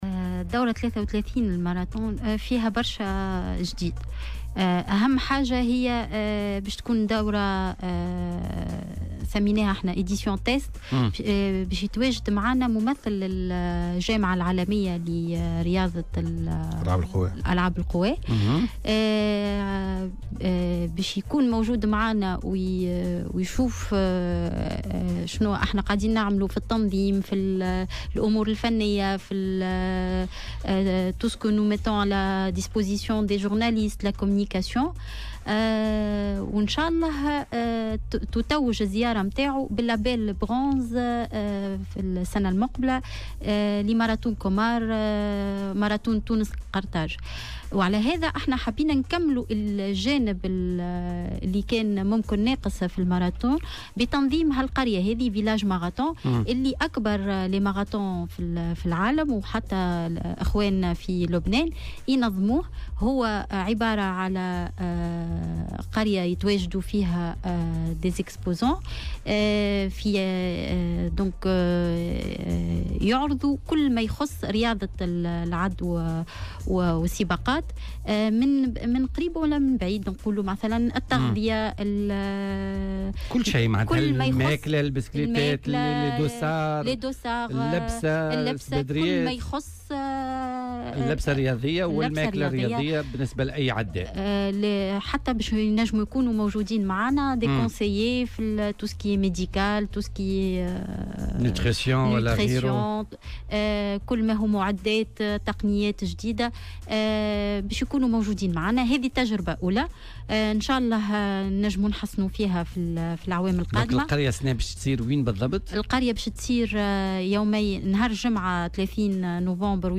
وأضافت في مداخلة لها اليوم في برنامج "بوليتيكا" أن الجديد في هذه الدورة هو إحداث قرية لعرض كل ما يخص رياضة العدو والسباقات، ستكون مفتوحة للعموم يومي 30 نوفمبر و 1 ديسمبر 2018 ، كما سيتم تخصيص برنامج تنشيطي ثري ومتنوّع. وأكدت أن رئيس الحكومة، يوسف الشاهد سيشرف على افتتاح القرية يوم الجمعة القادمة، مشيرة أيضا إلى أن وسائل إعلام أجنبية عبّرت عن رغبتها في مواكبة فعاليات الماراطون.